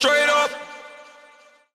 Vox